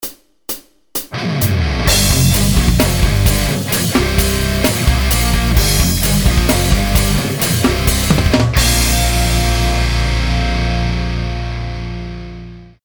Toto aj s podkladom: